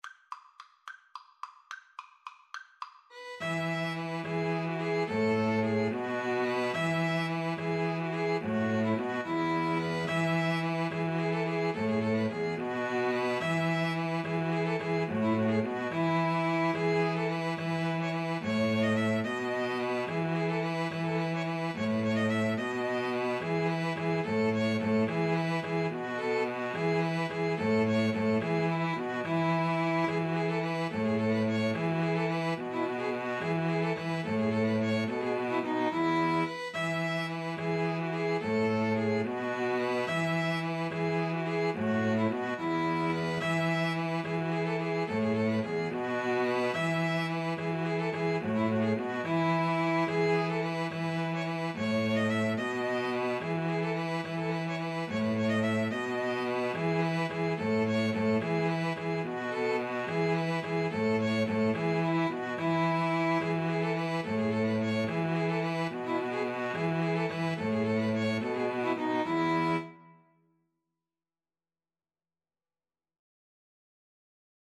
Free Sheet music for String trio
E major (Sounding Pitch) (View more E major Music for String trio )
6/4 (View more 6/4 Music)
Traditional (View more Traditional String trio Music)